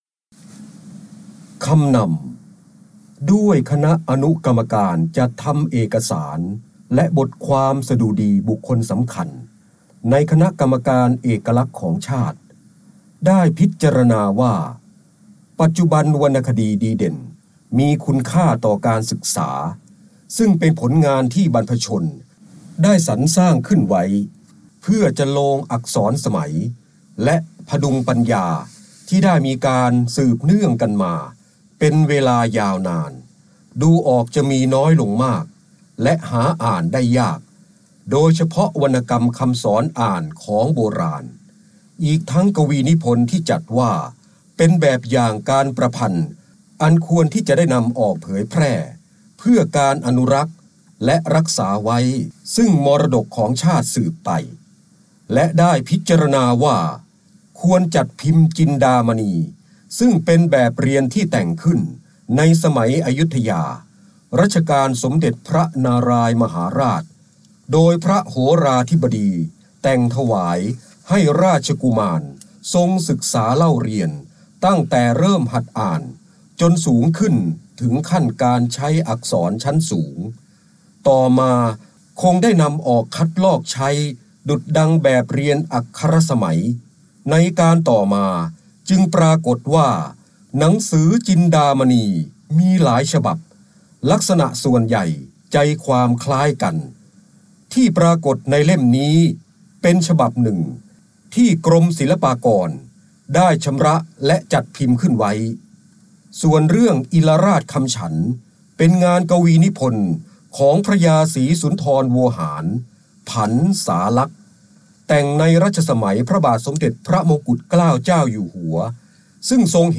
เสียงบรรยายจากหนังสือ จินดามณี คำนำ 1